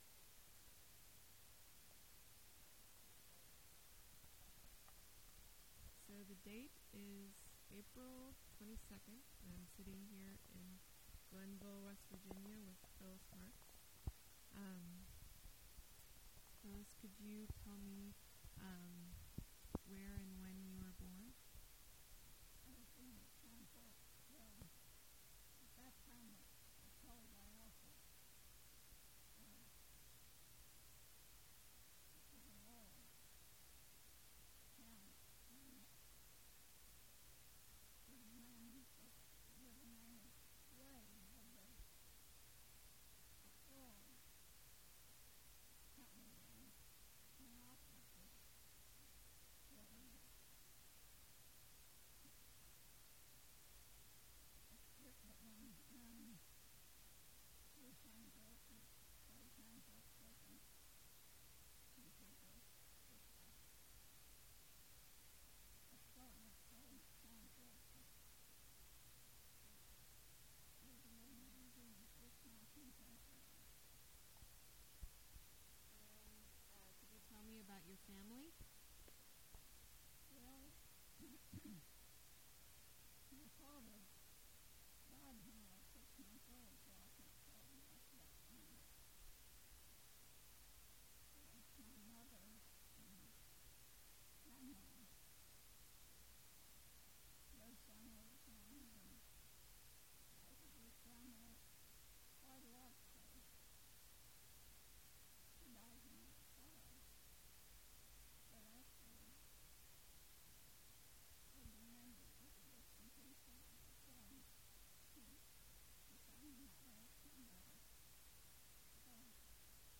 Oral history
Interviewers notes:I went to interview 88-year old ballad singer
She said she was hoarse today, but would sing for me another time.